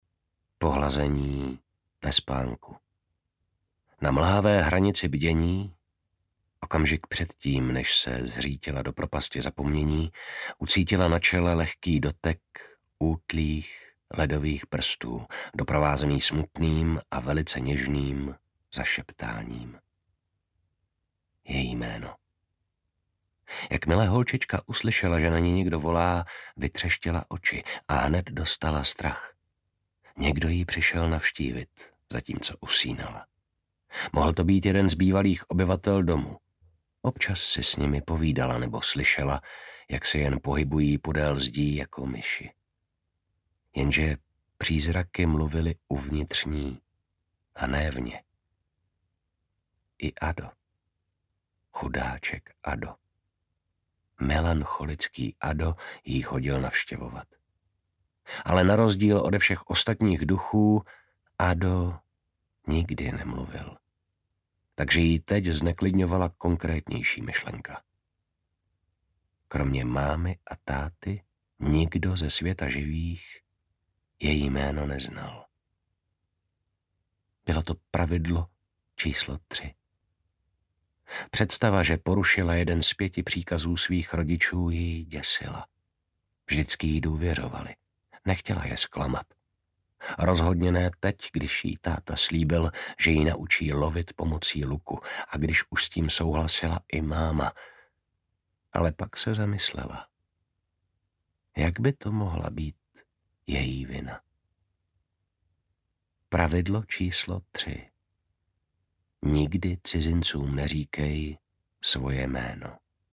Dům hlasů audiokniha
Ukázka z knihy